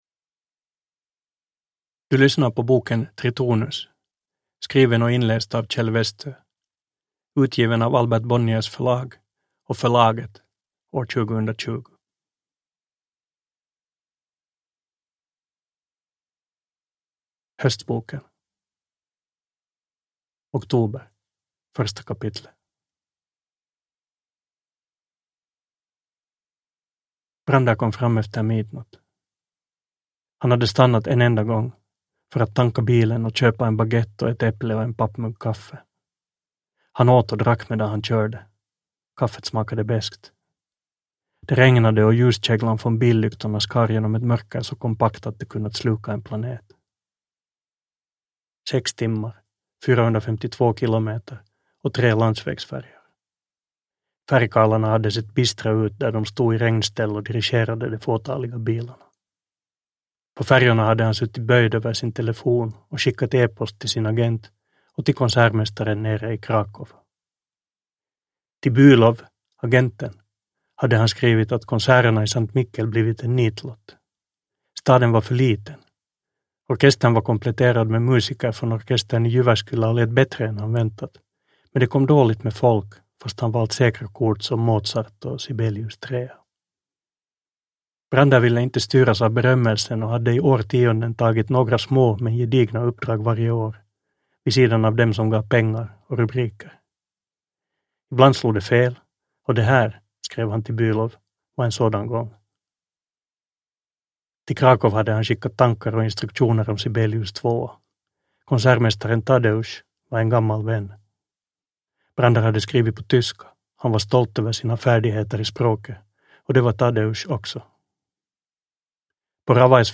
Uppläsare: Kjell Westö
Ljudbok